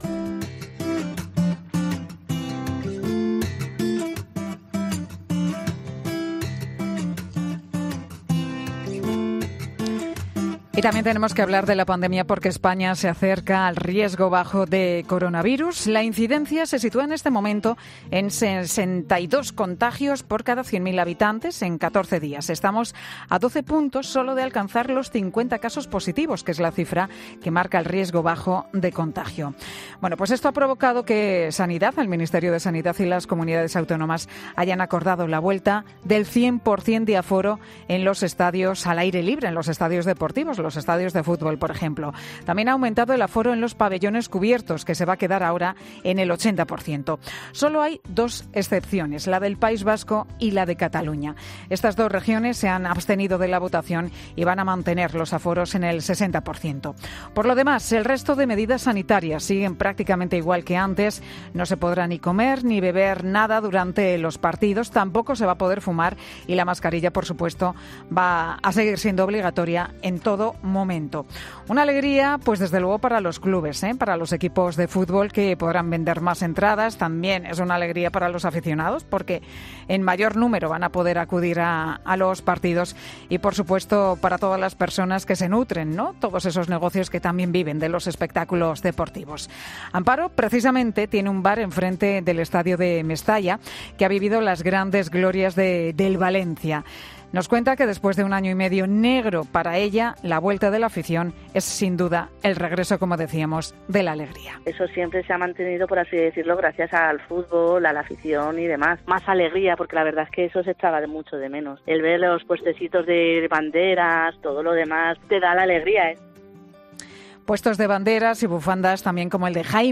En Mediodía COPE hablamos con negocios y comercios cercanos a estadios de fútbol para conocer como se han tomado ellos esta noticia